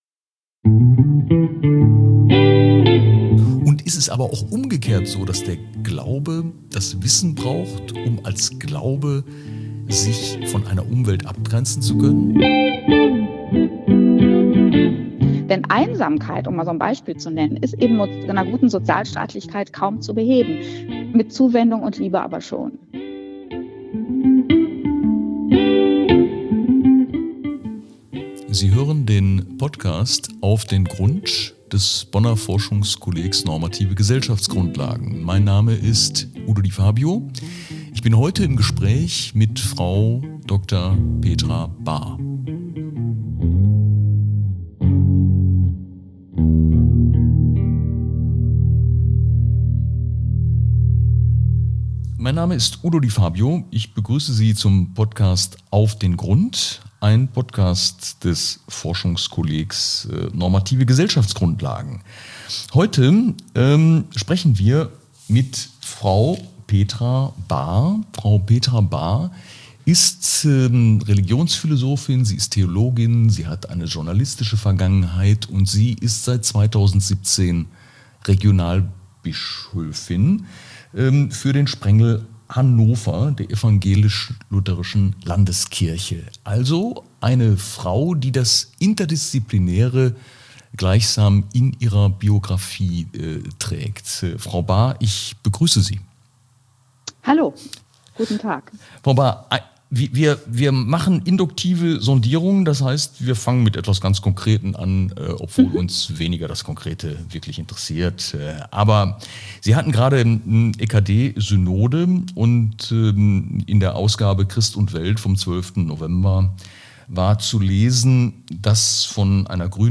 "Auf den Grund!" lädt zur Wissenschaft im Gespräch. Der Gastgeber ist Professor Udo Di Fabio von der Universität Bonn, Verfassungsrechtler, Gründungsdirektor des Forschungskolleg normative Gesellschaftsgrundlagen (FnG) und ehemaliger Richter des Bundesverfassungsgerichts. Er diskutiert mit Vertretern unterschiedlicher Disziplinen und auch mit dem wissenschaftlichen Nachwuchs aktuelle Themen aus Politik, Wirtschaft und Gesellschaft.